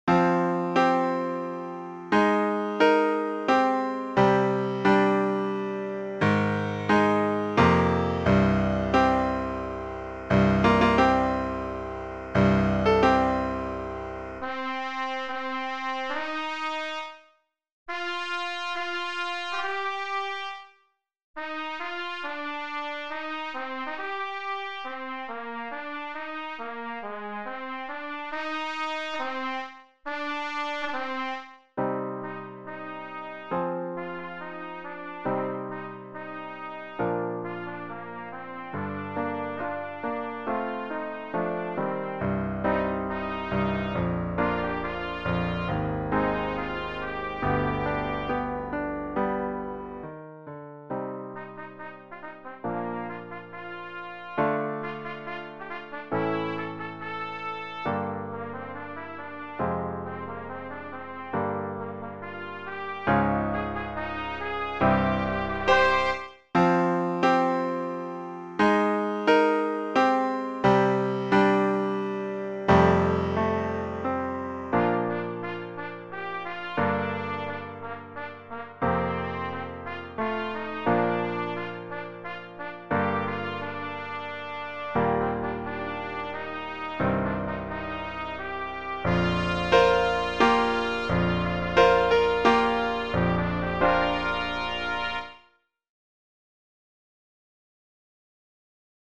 Pour trompette (ou cornet) et piano